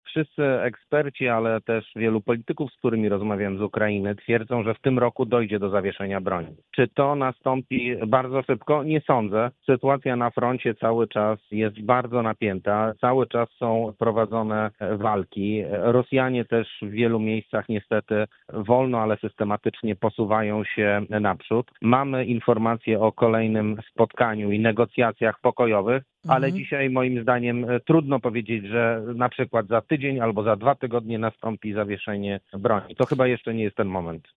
Michał Dworczyk był gościem wrocławskiego radia „Rodzina”